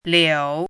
“珋”读音
liǔ
珋字注音：ㄌㄧㄡˇ
国际音标：liou˨˩˦